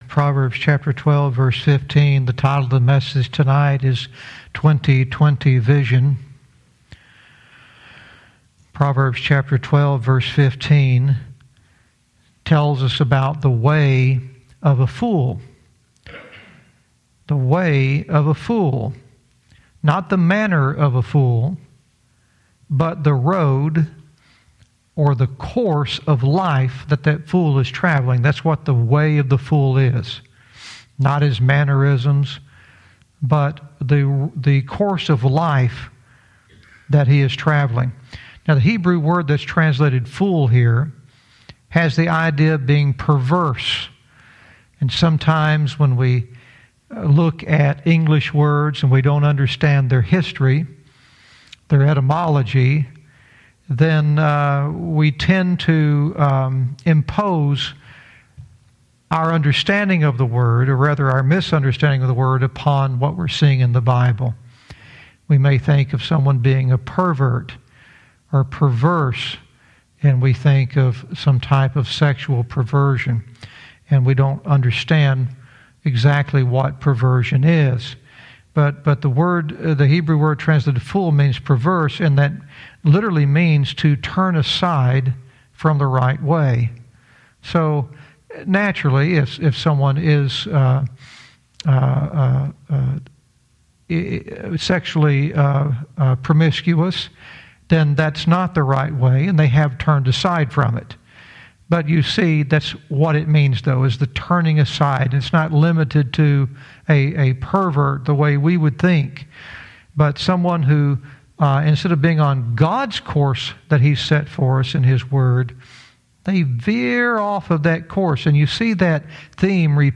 Verse by verse teaching - Proverbs 12:15 "20/20 Vision"